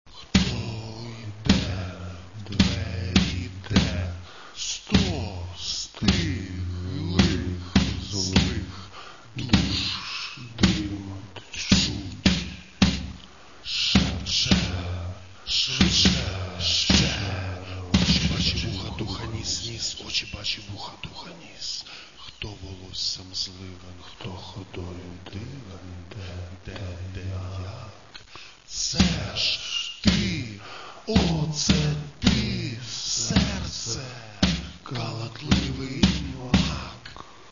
Рок та альтернатива